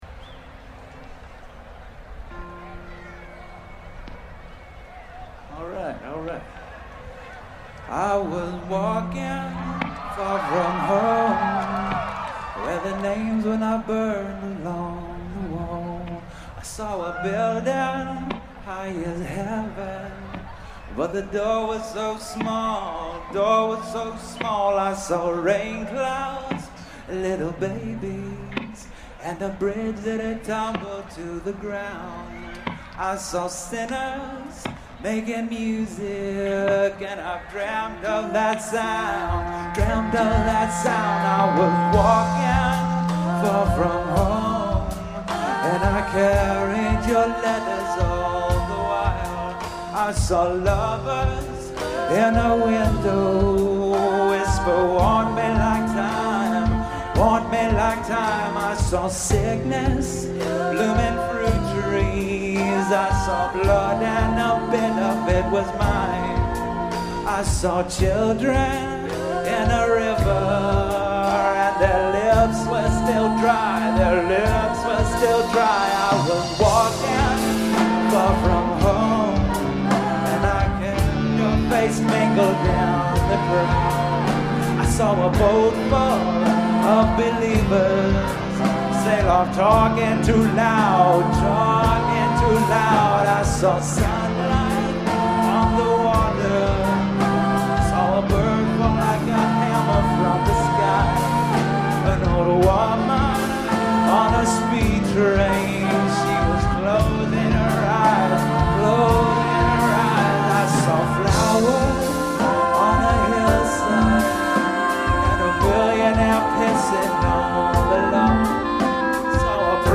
live at Sasquatch 2011